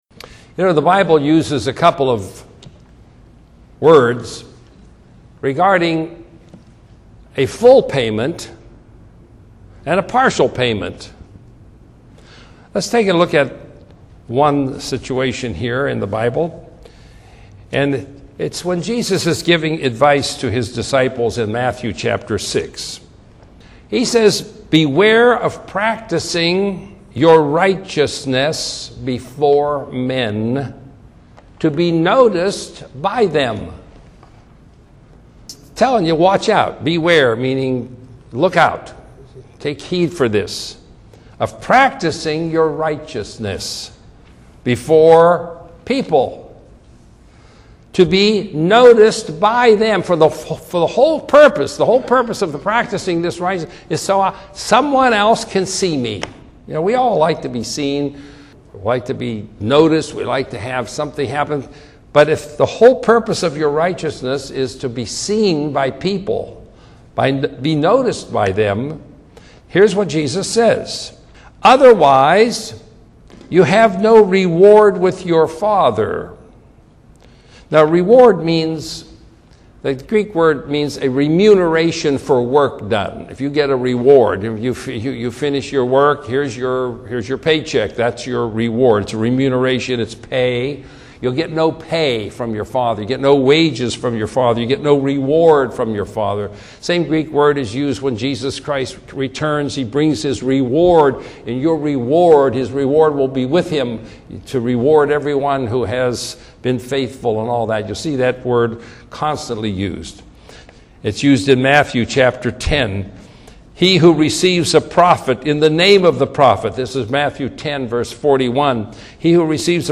Audio Sermons 2014